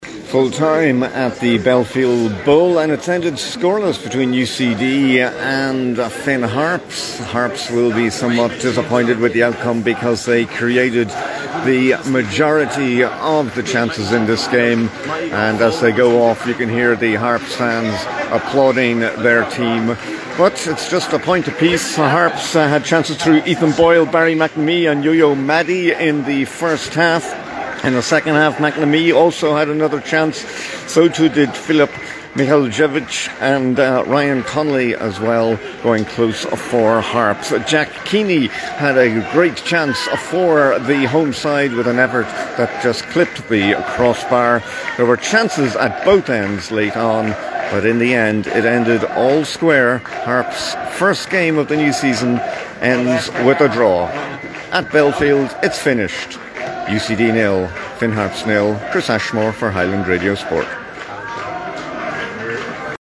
reports from the Belfield Bowl for Highland Radio Sport
Harps-UCD-FT-REPORT.mp3